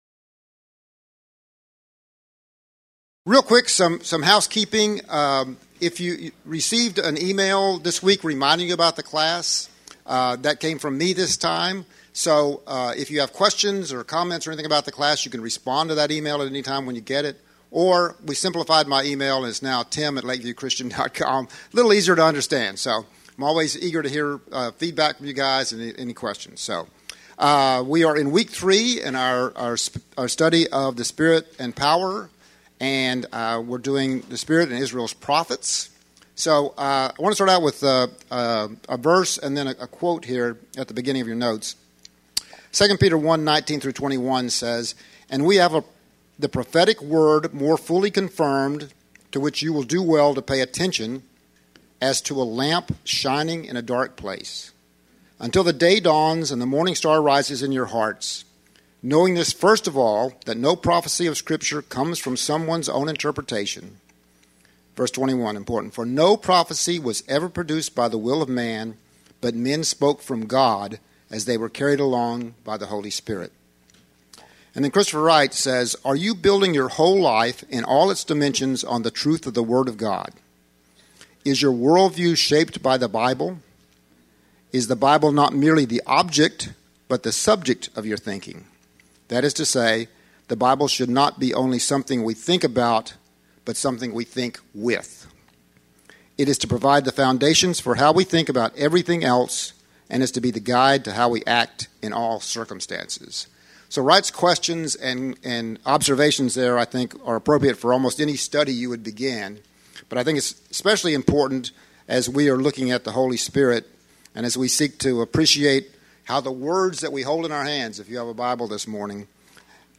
Teachings given in the LCC School of the Word Class